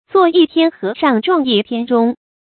注音：ㄗㄨㄛˋ ㄧˋ ㄊㄧㄢ ㄏㄜˊ ㄕㄤˋ ㄓㄨㄤˋ ㄧˋ ㄊㄧㄢ ㄓㄨㄙ